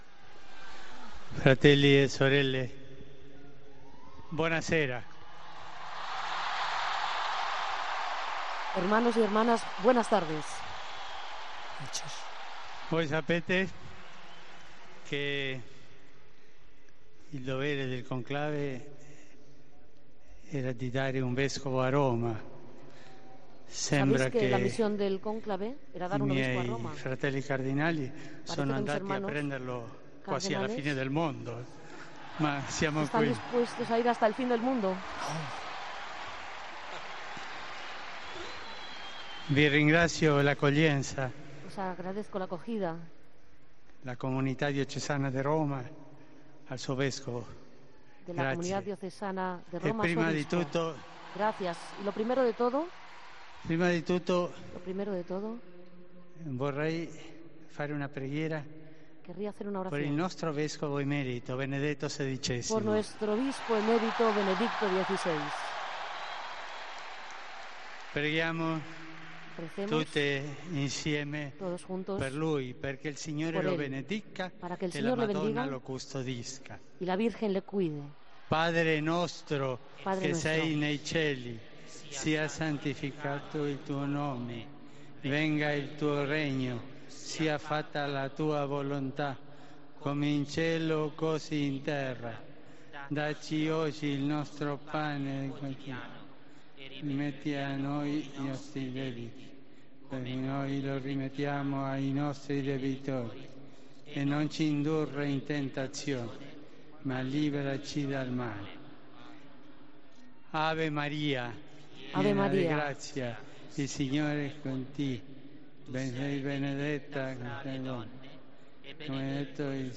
El primer discurso del Papa Francisco